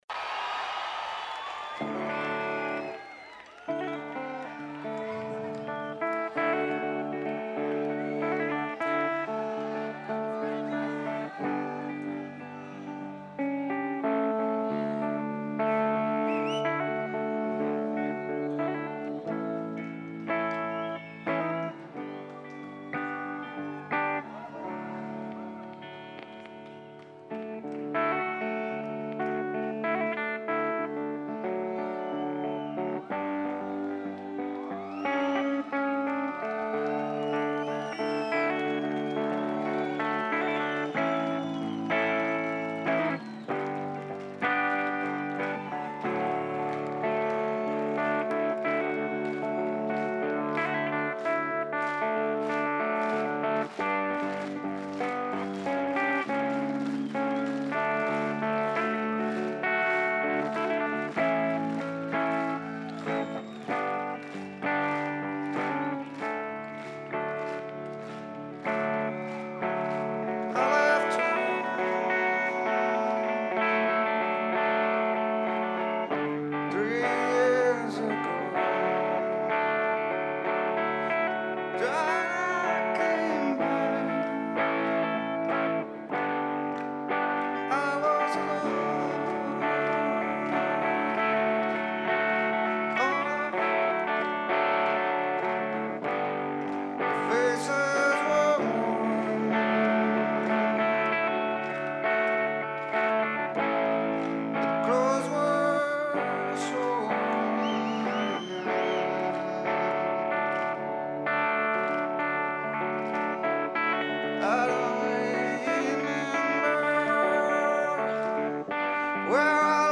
Residenzplatz: Salzburg, Austria